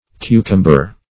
Cucumber \Cu"cum*ber\ (k?`k?m-b?r, formerly kou"k?m-b?r), n.